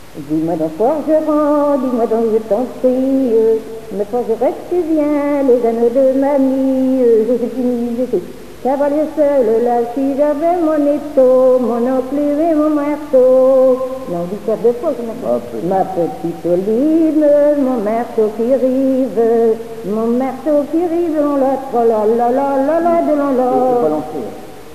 danse : quadrille
Pièce musicale inédite